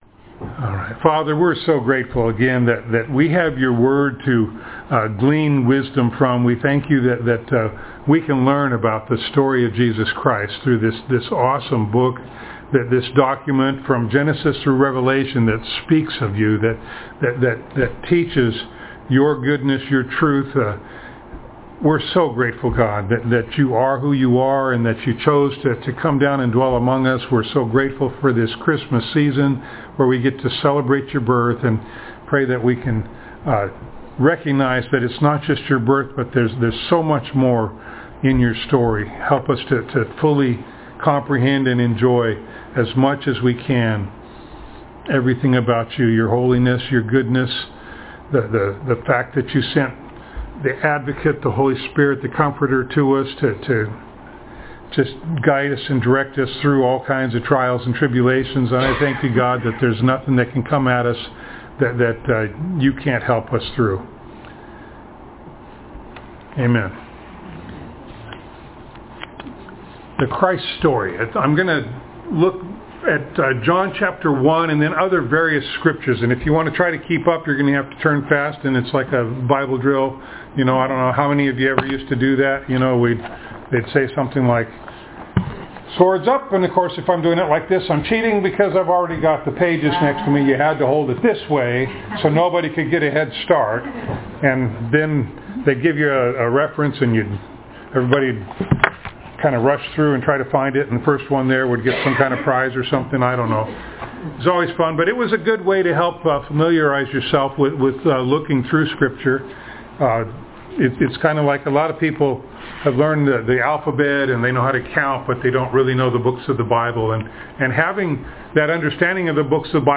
Passage: John 1, Genesis 1, Psalm 100, Isaiah 53, Luke 1, Luke 2, 1 Corinthians 15, 2 Corinthians 5, Jeremiah 23 John 3, Romans 8 Service Type: Sunday Morning